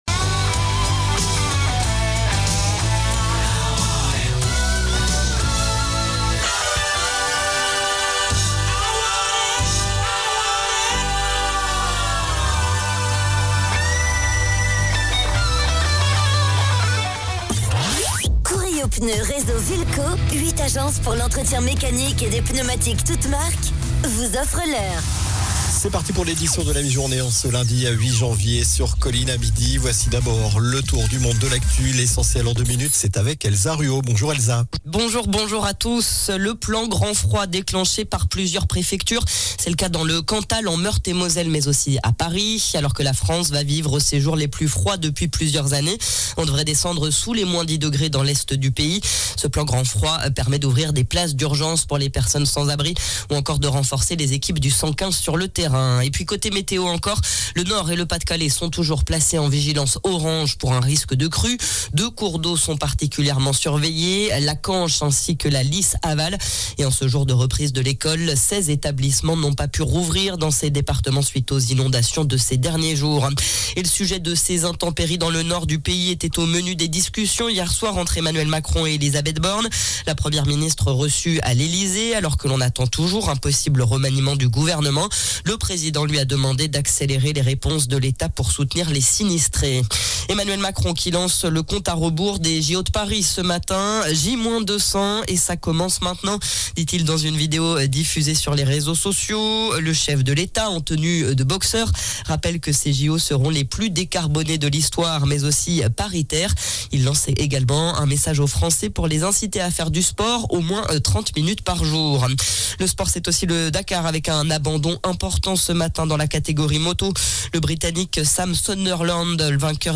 JOURNAL DU LUNDI 08 JANVIER ( MIDI )